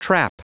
Prononciation du mot trap en anglais (fichier audio)
Prononciation du mot : trap